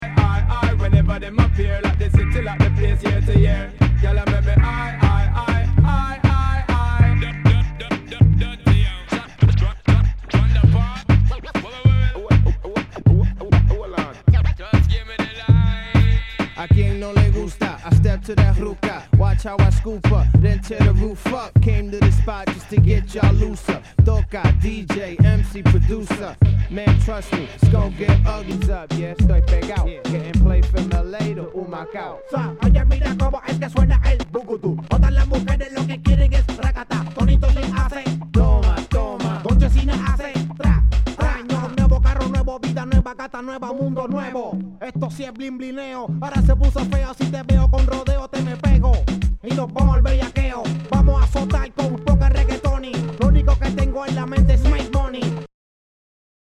HIPHOP/R&B
ナイス！ラガ・ヒップホップ！
全体にチリノイズが入ります